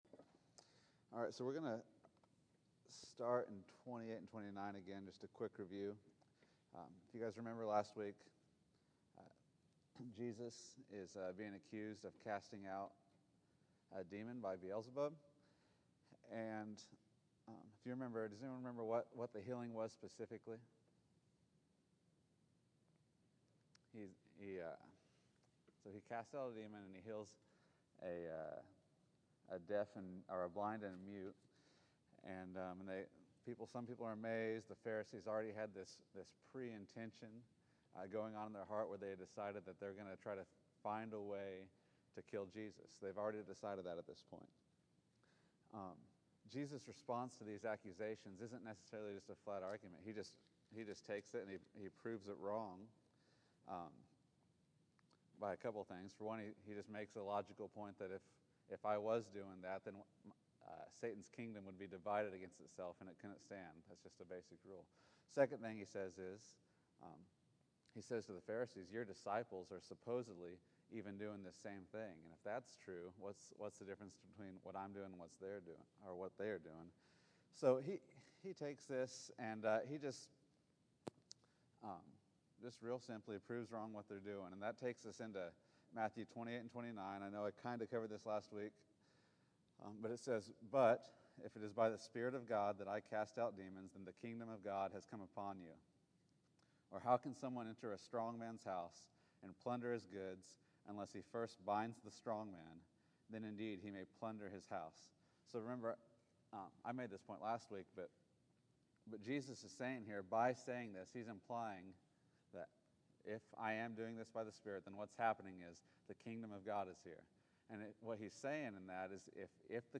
Matthew 12:30-32 August 18, 2013 Category: Sunday School | Location: El Dorado Back to the Resource Library Jesus' Kingdom will scatter those who are not his, neutrality is not an option.